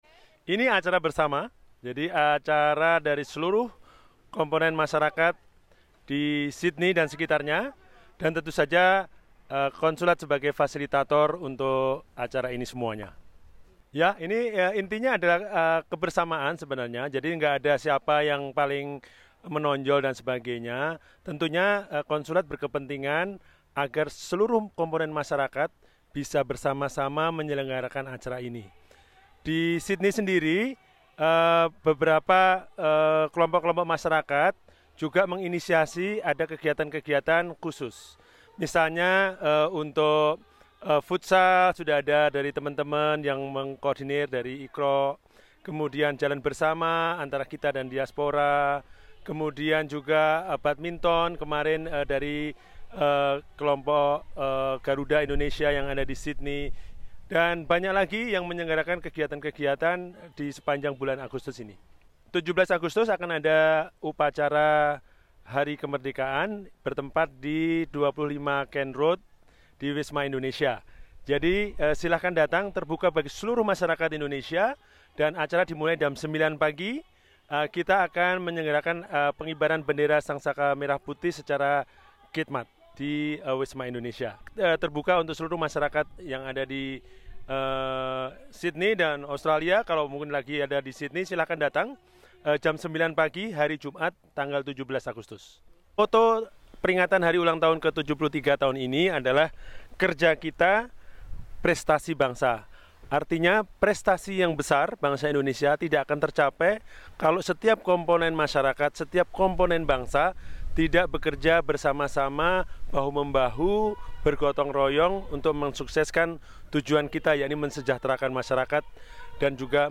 Taking place at the Addison Road Community Centre, Marrickville, this get-together was a commemoration of Indonesia's 73ʳᵈ Independence Day. Consul General in Sydney, Mr Heru Hartanto Subolo, spoke to SBS Indonesian on this matter as well as his hopes for the Indonesia's 73ʳᵈ anniversary.